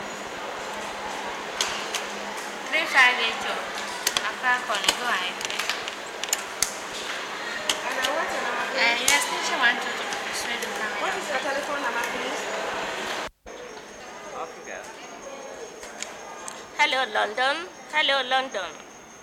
Telephone Operators in a Ghana Exchange 1961
This effect is in two parts. First is general atmos of the exchange the second half is an operator calling overseas exchanges.
Recorded on Jan 27th 1961 by he Gold Coast Film Unit